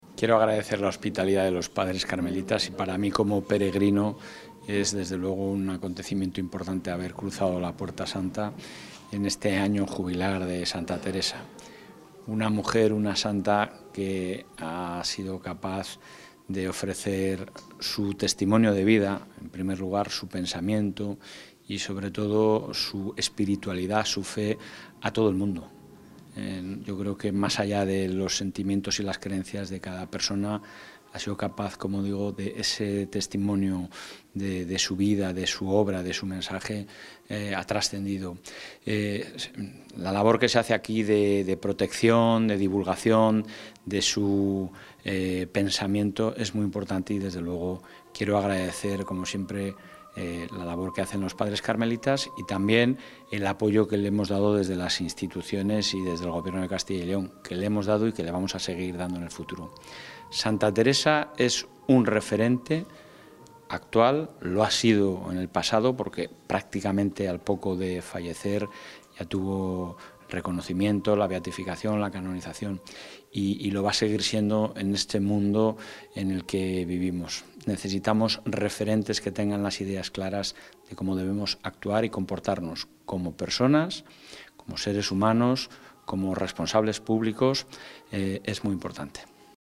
Intervención del presidente de la Junta.
El presidente de la Junta de Castilla y León, Alfonso Fernández Mañueco, ha visitado hoy en Ávila la Basílica y Casa Natal de Santa Teresa de Jesús con motivo del Año Jubilar Teresiano.